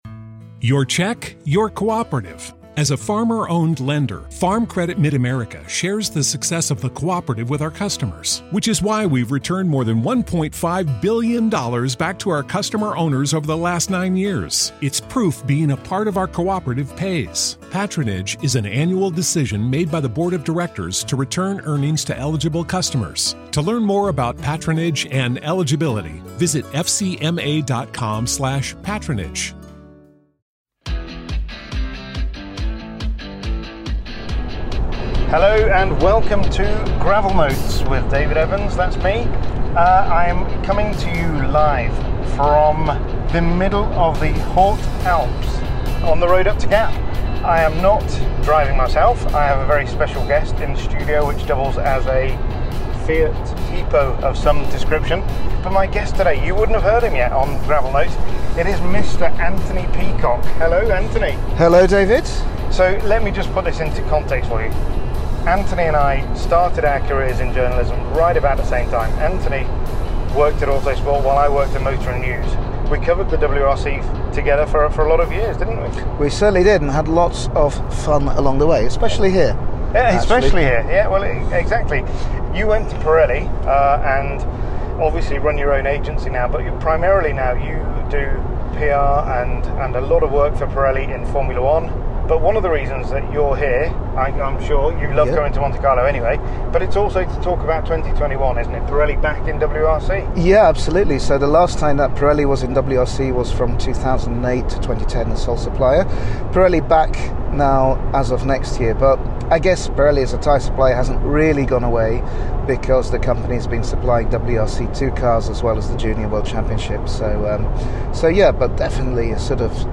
as they drive to Rally Monte-Carlo for the opening round of the season.